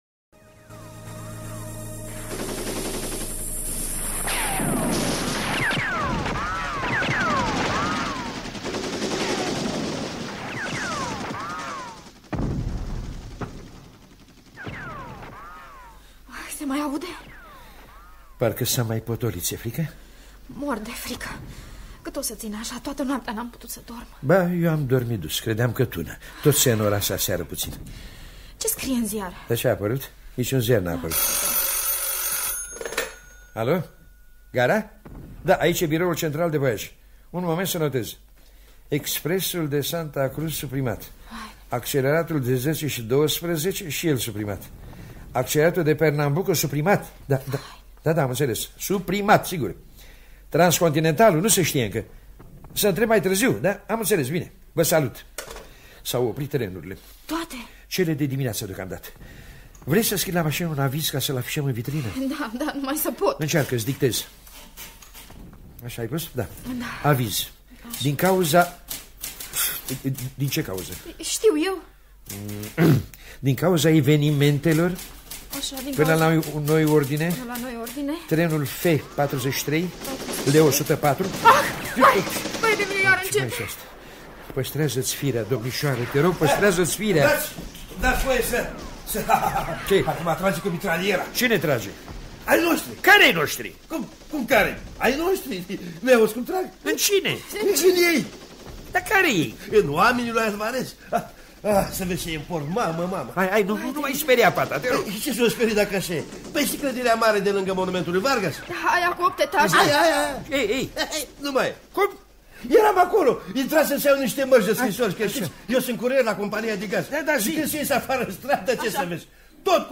Insula-Teatru-radiofonic-romantic.mp3